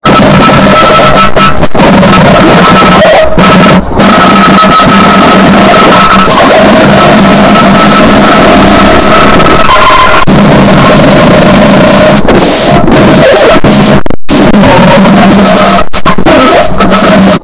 Nuke fart earrape - Botão de Efeito Sonoro